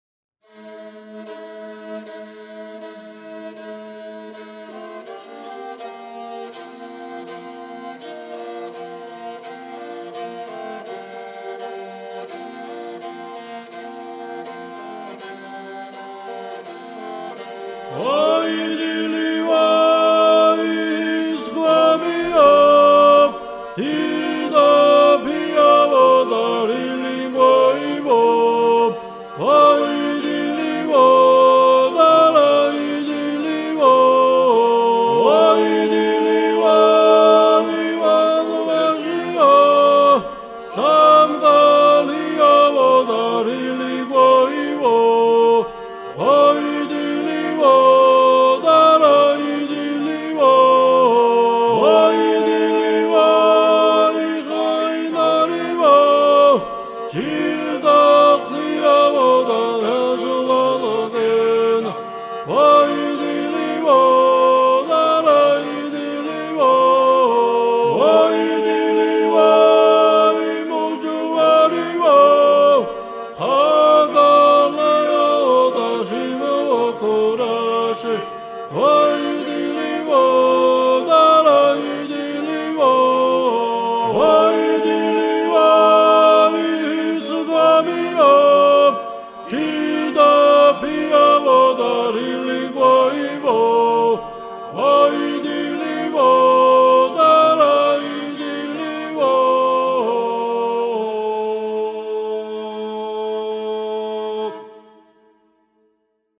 სასწავლო ჩანაწერი I ხმ